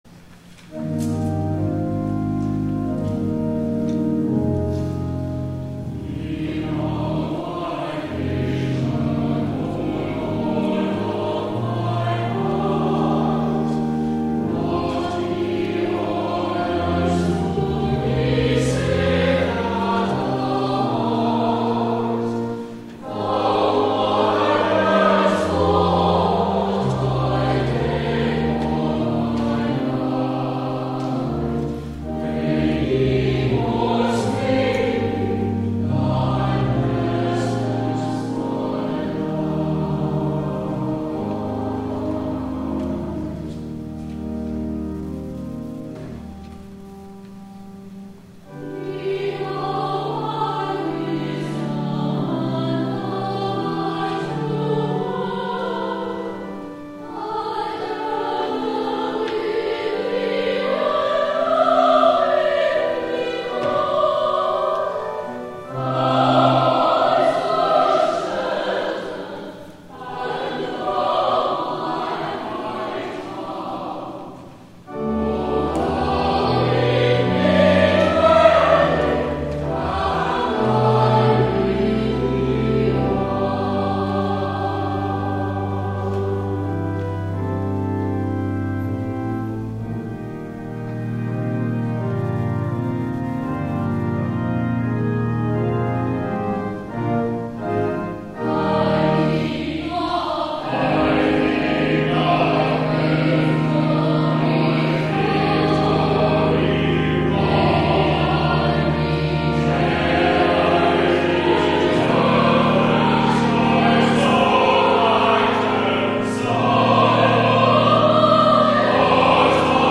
11 A.M. WORSHIP
THE ANTHEM